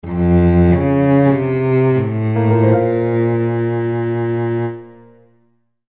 SFX悲伤伤心的音效下载
SFX音效